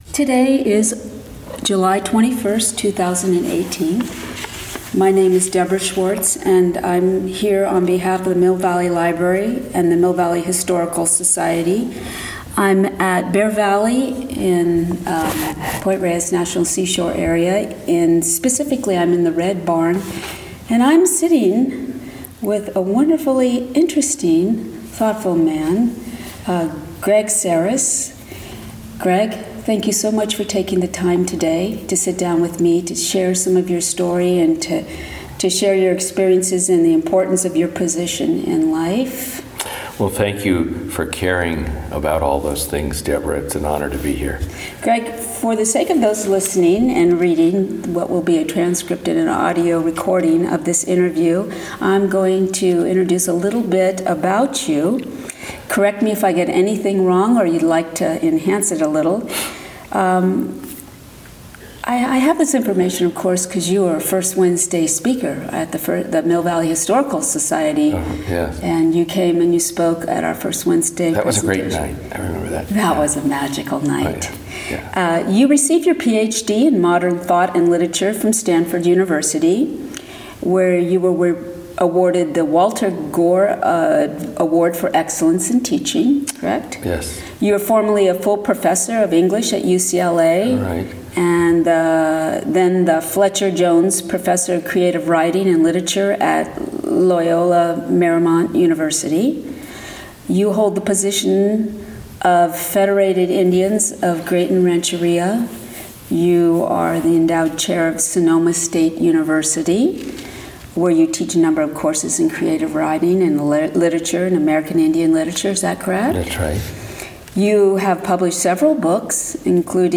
Title Oral History of Greg Sarris Collection Oral History Scope & Content In this oral history, author, scholar, and chairmen of the Federated Indians of Graton Rancheria Greg Sarris tells the story of his life as a journey of self-discovery and social commitment.